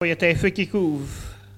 Soullans
Locutions vernaculaires